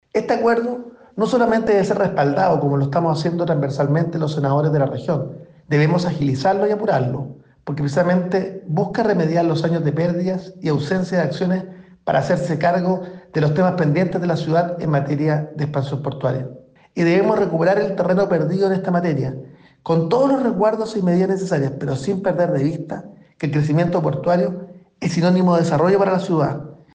El senador de Renovación Nacional, Francisco Chahuán, recalcó la importancia de este acuerdo para el Puerto de Valparaíso y lo relevante que es su implementación para el desarrollo económico de la ciudad. Eso sí, indicó que esto debe hacerse con todos los resguardos correspondientes.